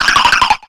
Cri de Balbuto dans Pokémon X et Y.